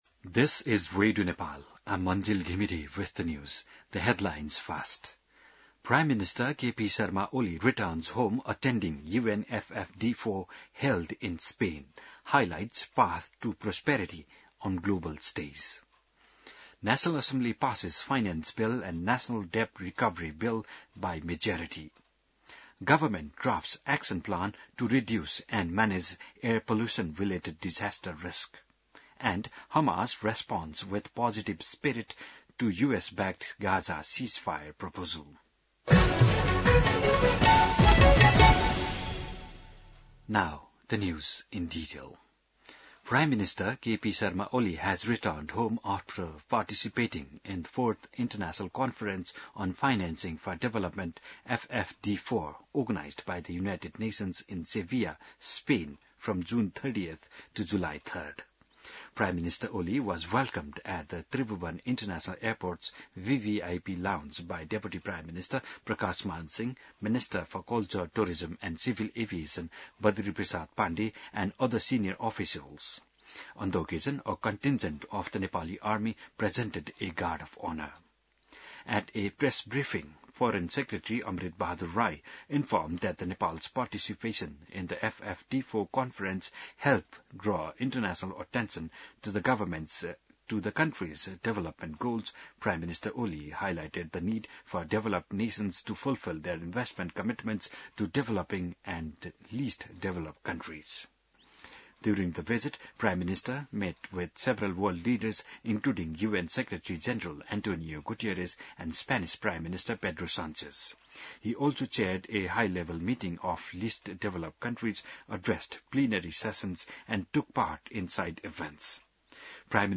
8 AM English News : 03 May, 2026